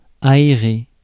Paris France (Île-de-France)